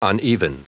Prononciation du mot : uneven